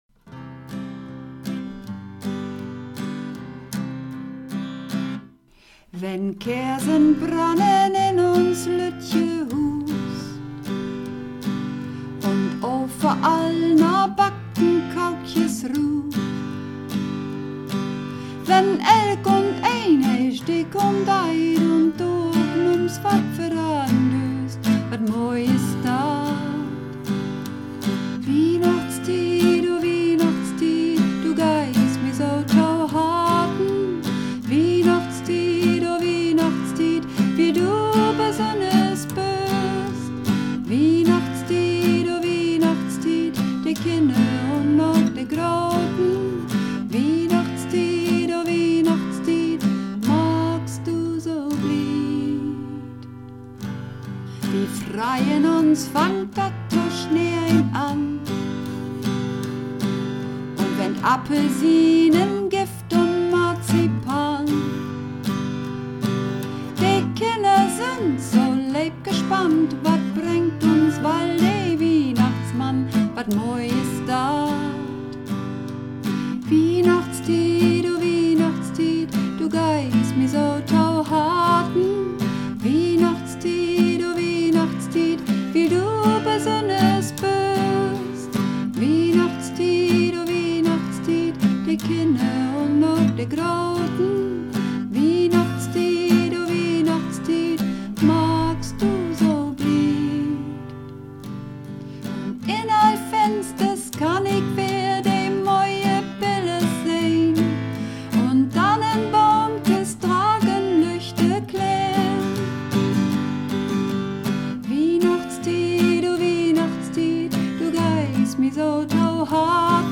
Übungsaufnahmen - Wiehnachtstied
Wiehnachtstied (Sopran)
Wiehnachtstied__3_Sopran.mp3